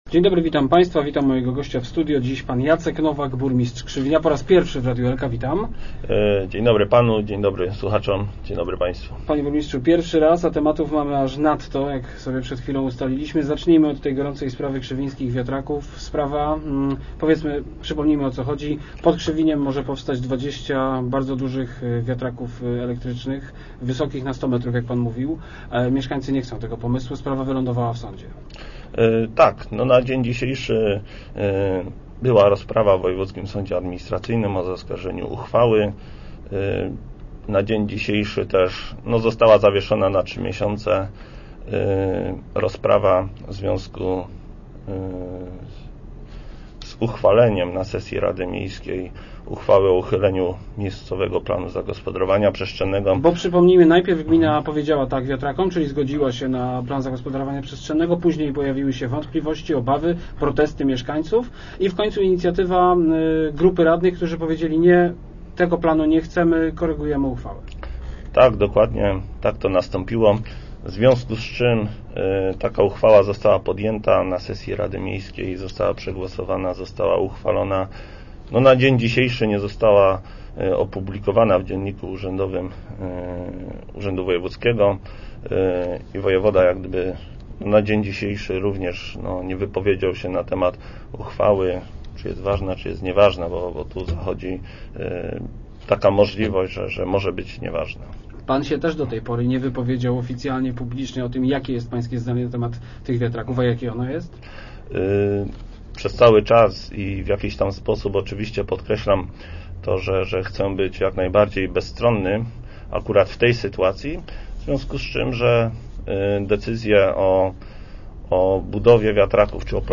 nowak_copy.jpg- Decyzje o budowie wiatraków podejmował mój poprzednik i ja nie mogę odpowiadać za brak komunikacji z mieszkańcami - mówił dziś w Rozmowach Elki burmistrz Krzywinia, Jacek Nowak. Sprawa budowy 20 elektrycznych siłowni pod Krzywiniem trafiła do sądu administracyjnego.